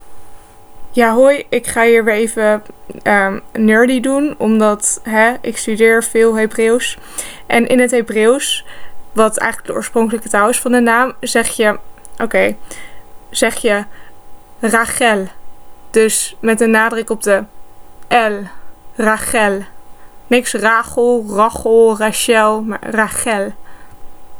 Hebreeuwse uitspraak Rachel
Sorry voor de kneuzige opname haha